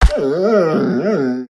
Minecraft Version Minecraft Version latest Latest Release | Latest Snapshot latest / assets / minecraft / sounds / mob / wolf / grumpy / death.ogg Compare With Compare With Latest Release | Latest Snapshot
death.ogg